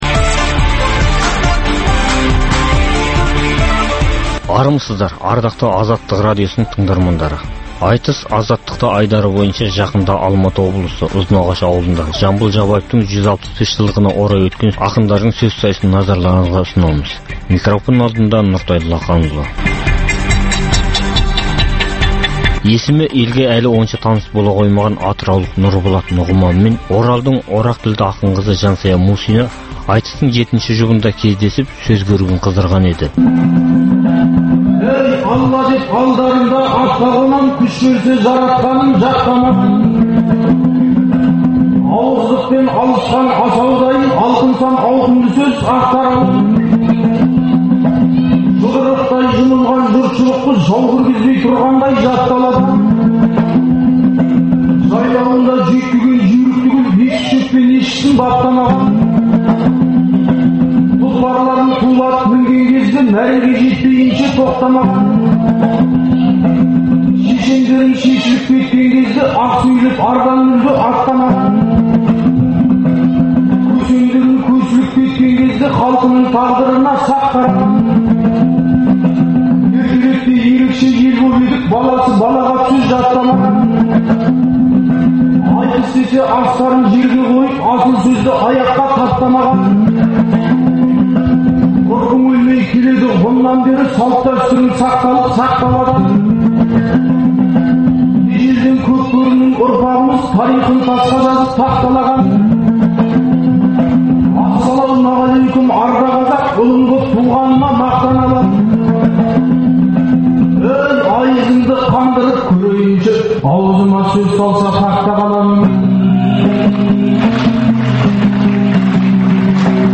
Жақында жыр алыбы - Жамбылдың 165 жылдығына арналып, Алматы облысының Ұзынағаш ауданында өткен ақындар айтысына қатысқан ақындардың жыр сайысымен таныстыруымызды әрі қарай жалғастырамыз.